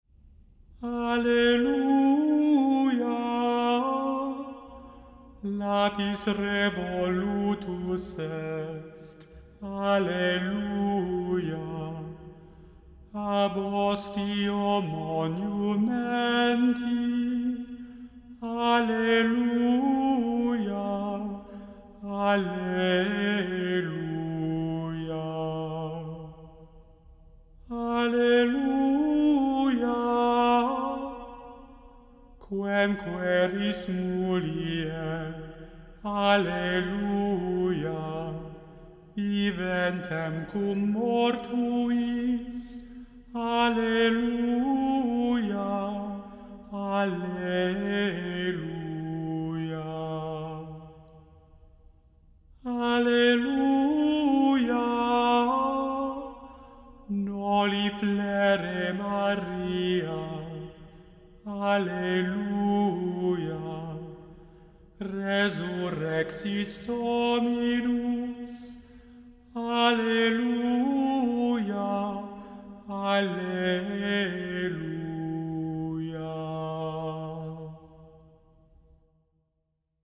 The Chant Project – Chant for Today (August 7) – Alleluia lapis revolutus est